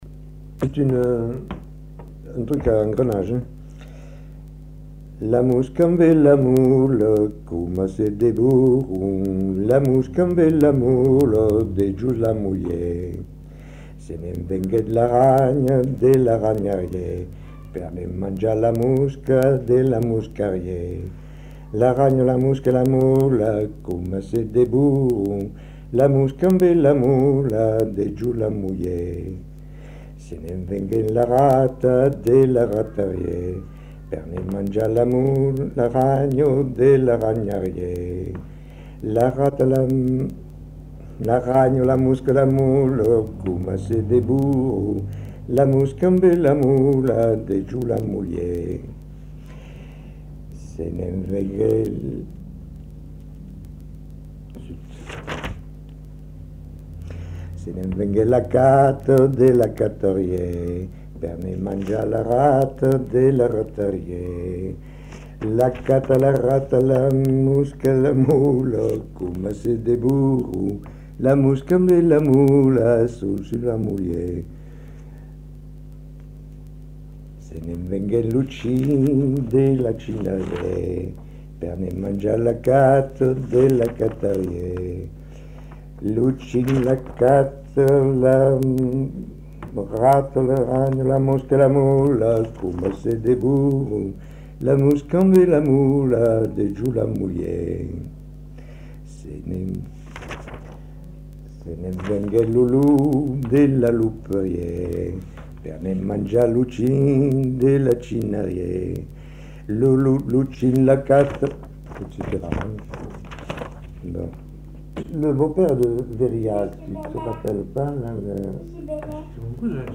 Lieu : Lodève
Genre : chant
Effectif : 1
Type de voix : voix d'homme
Production du son : chanté